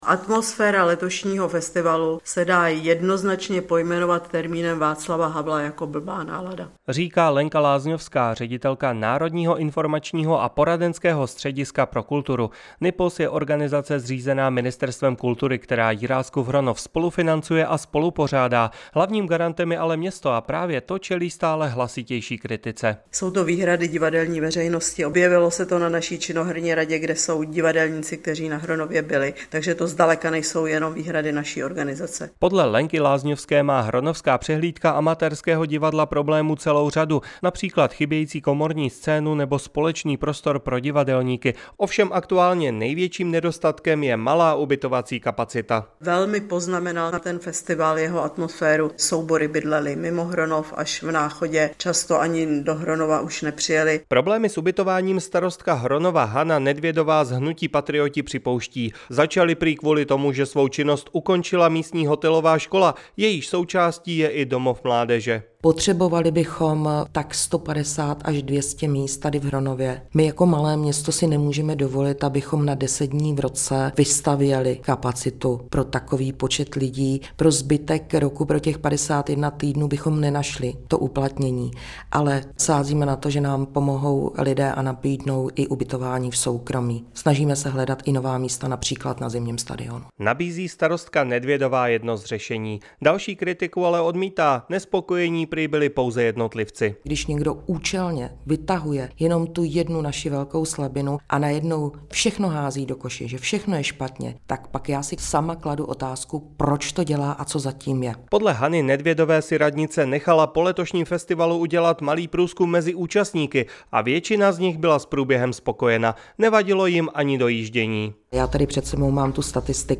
Hronov, Problémy Jiráskova Hronova, reportáž pro Radiožurnál, 2015